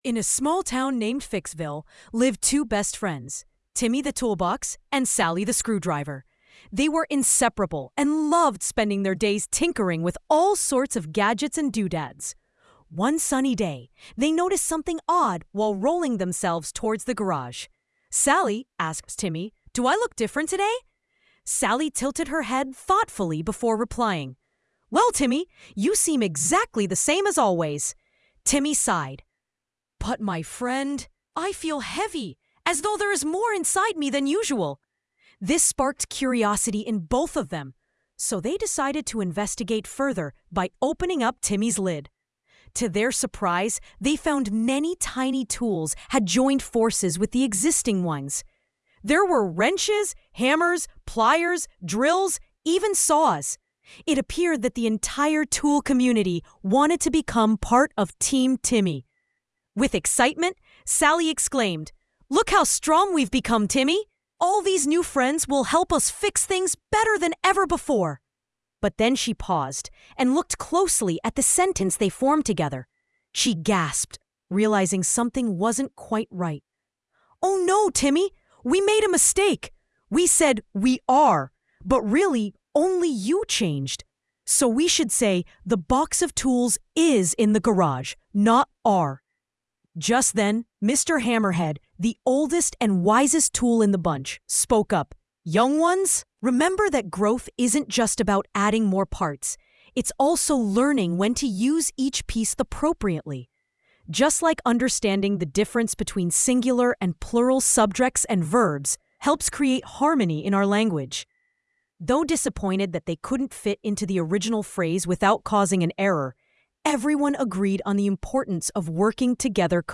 story
tts